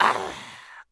minf_fall_v.wav